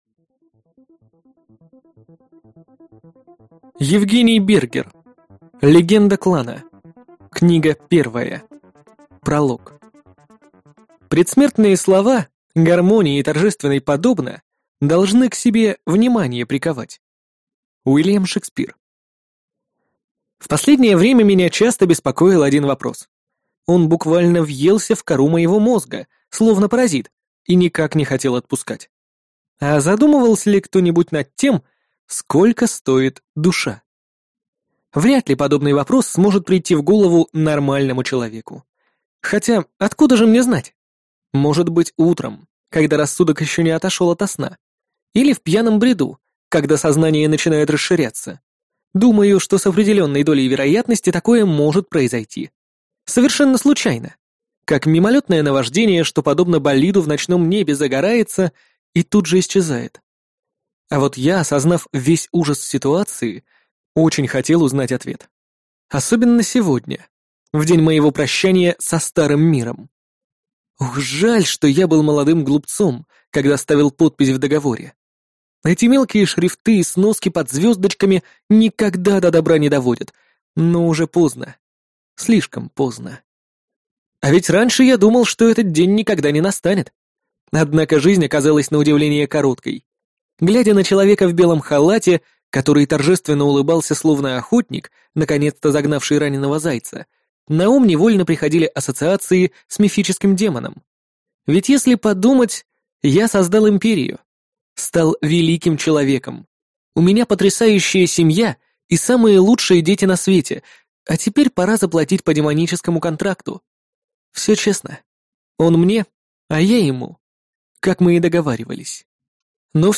Аудиокнига Легенда Клана | Библиотека аудиокниг